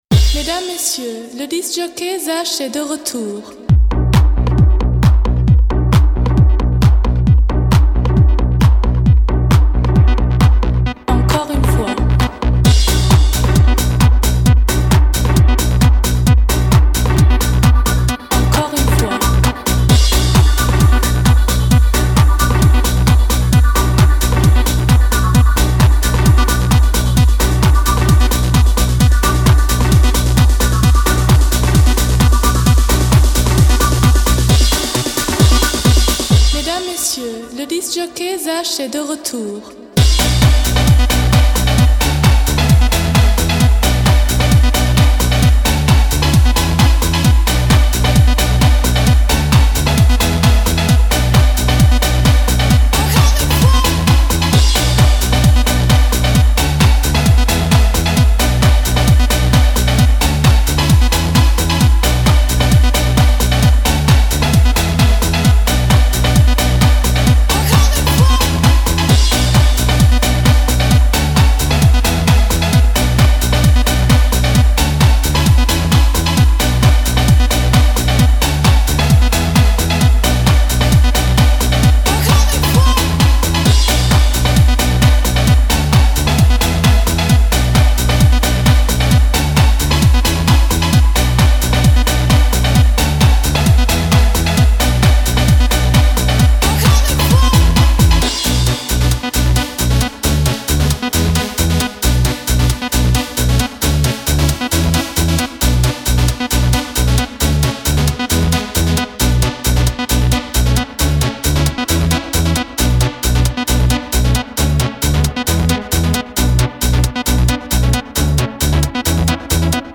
Жанр: Dance